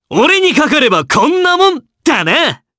Gladi-Vox_Victory_jp.wav